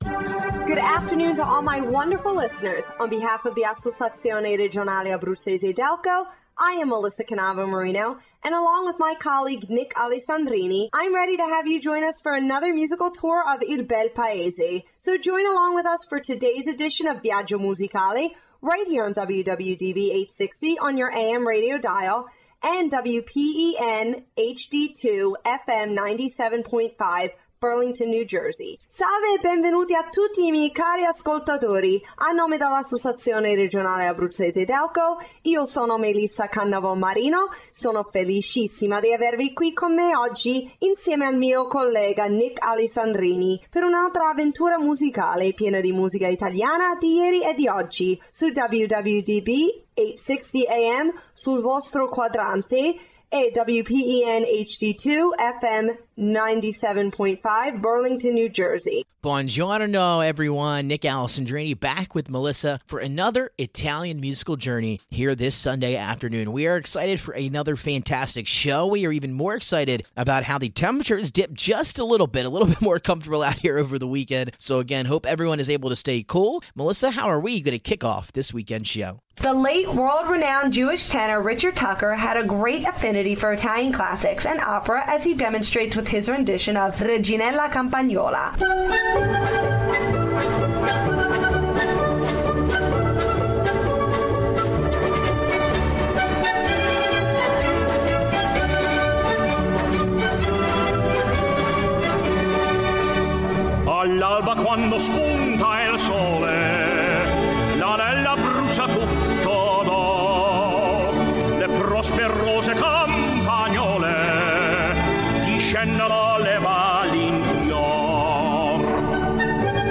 Bi-lingual!